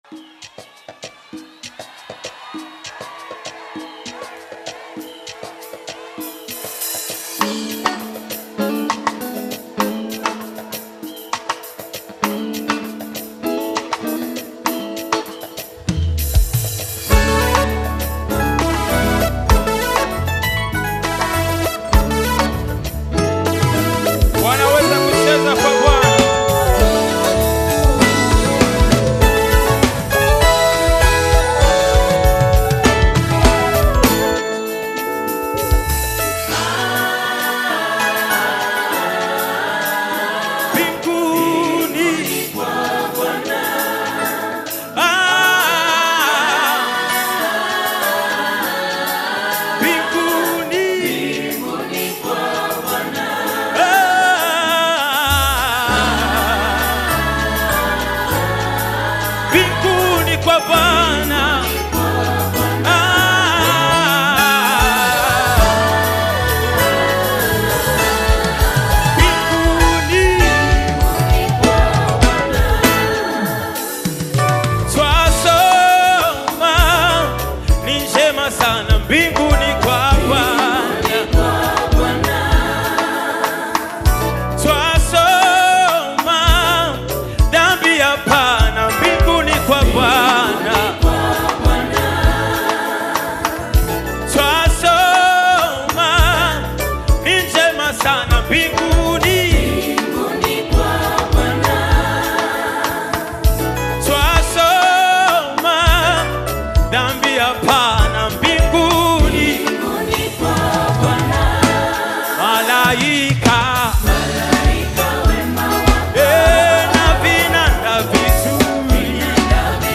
Tanzanian gospel praise team
gospel song
African Music